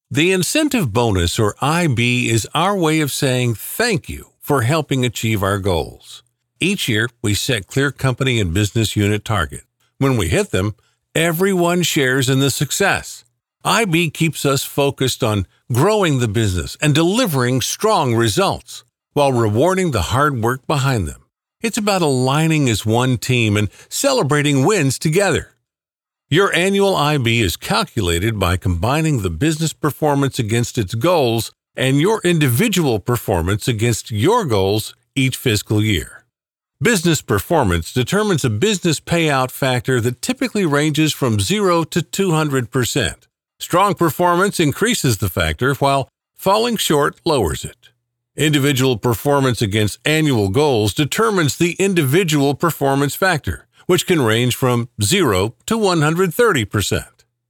Male
From friendly and smooth to sincere, authoritative, and dynamic, YouTube narrations, explainers, and podcast intros.
E-Learning
Explainer Video